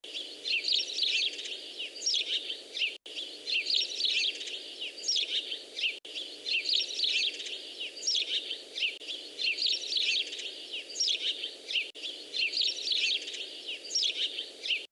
CANTO PASSERO.wav